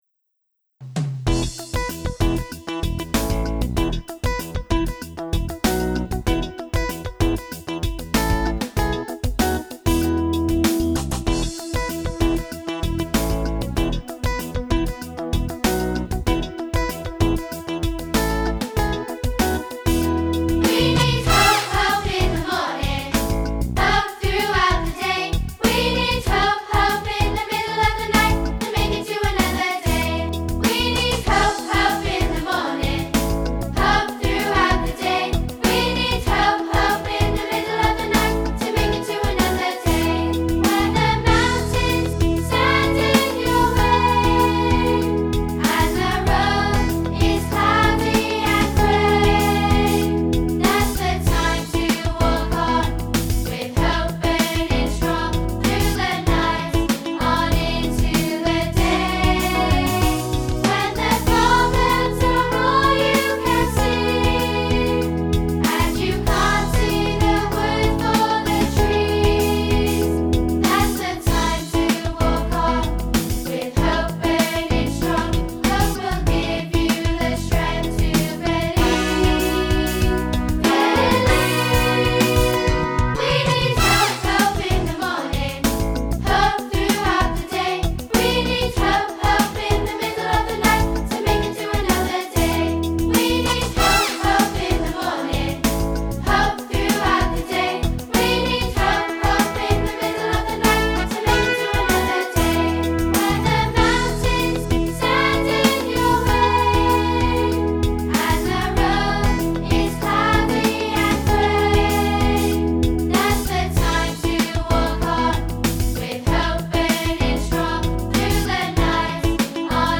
Key: E
Vocal range: C sharp - C sharp